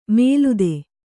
♪ mēlude